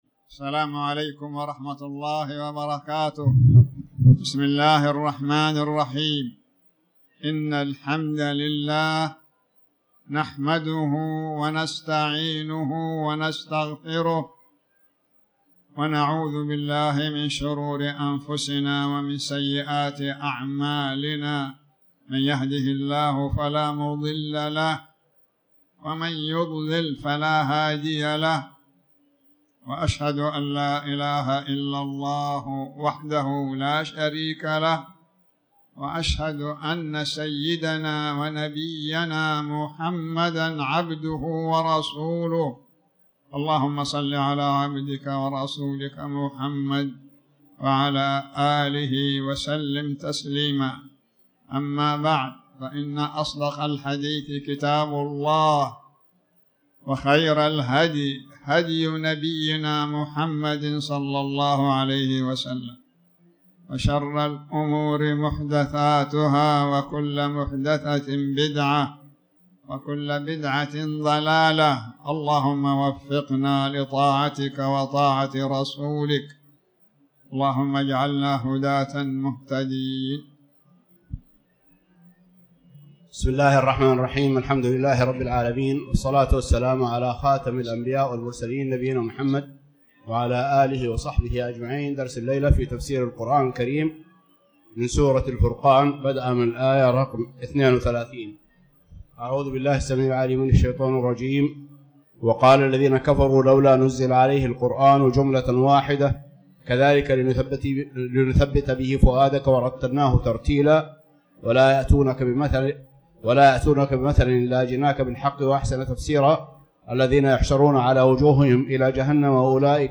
تاريخ النشر ٢٠ رجب ١٤٤٠ هـ المكان: المسجد الحرام الشيخ